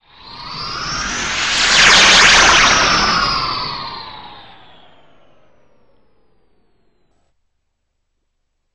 teleport2.wav